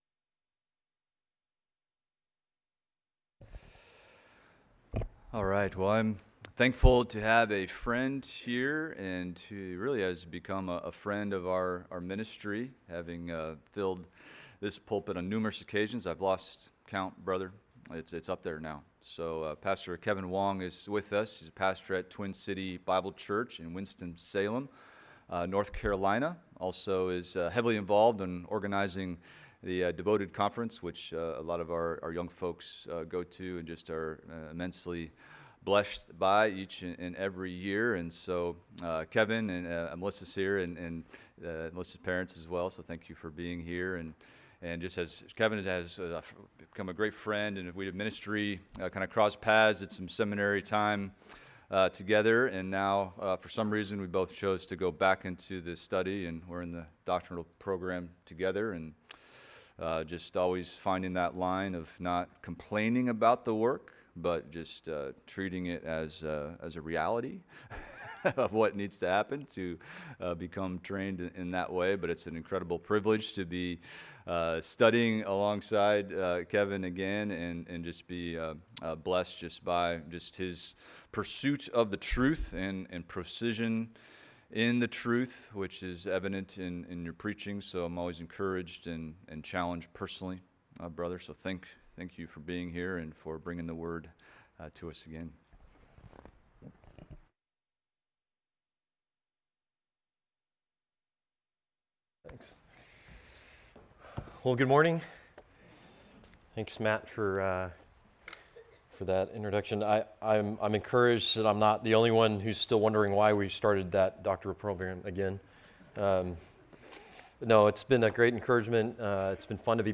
Expository Preaching from the Psalms – Psalm 119:97-104 - Treasuring the Work of the Word
Psalm 119:97-104 Sermon Outline